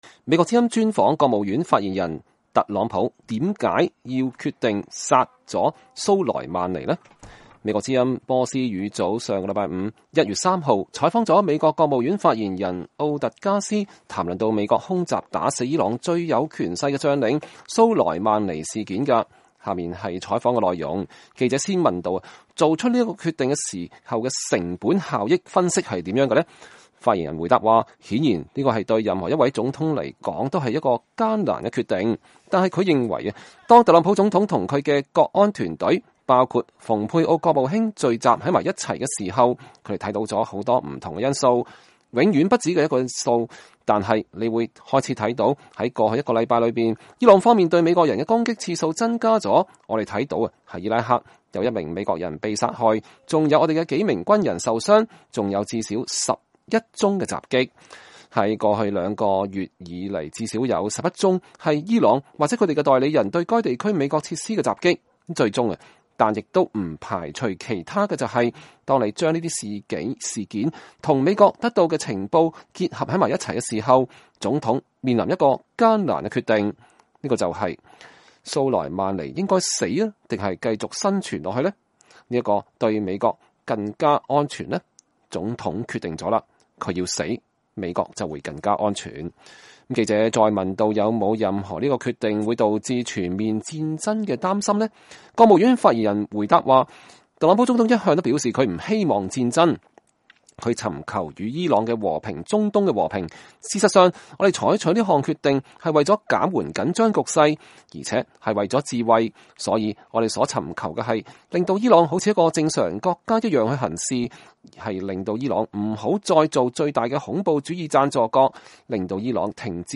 美國之音(VOA)波斯語組星期五(1月3日)採訪了美國國務院發言人摩根·奧特加斯，談論美國空襲打死伊朗最有權勢的將領卡西姆·蘇萊曼尼事件。